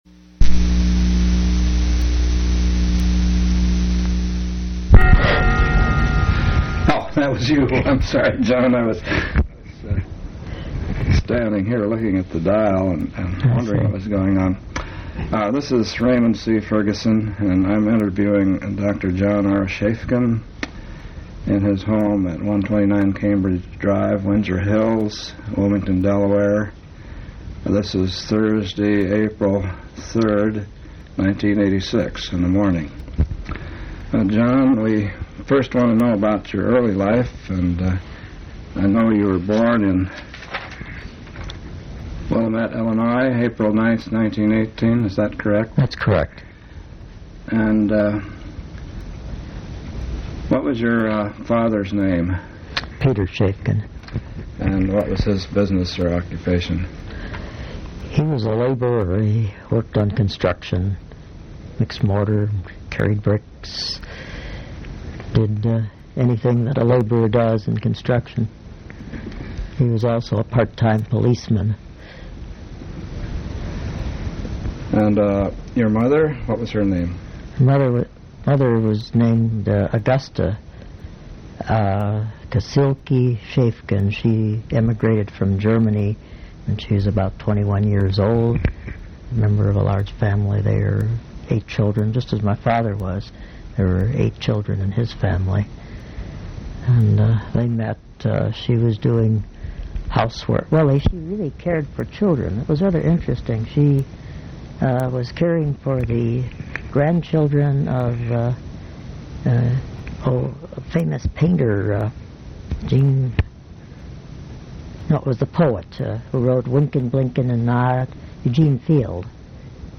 Genre Oral histories